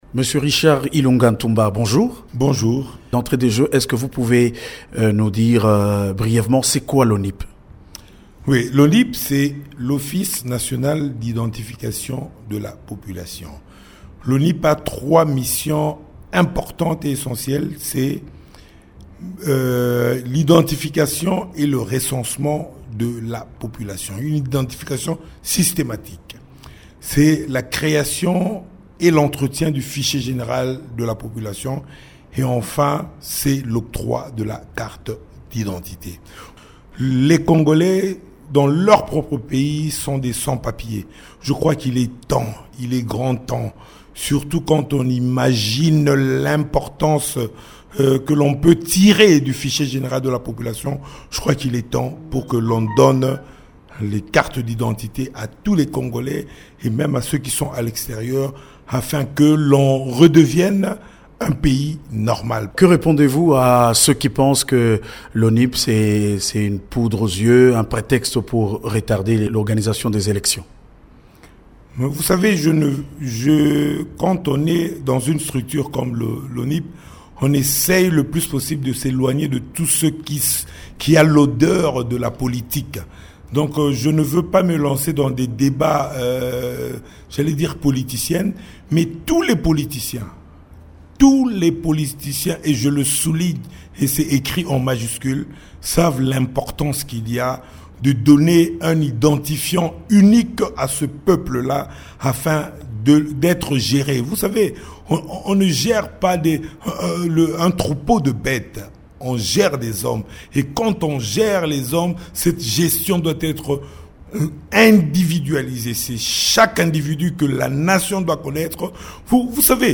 Richard Ilunga, directeur général de l’Office nationale de l’identification de la population (ONIP), souligne, mercredi 10 mars dans un entretien à Radio Okapi, l’importance de l’identification des Congolais. Il lance un appel pour que cette identification intervienne le plus vite possible.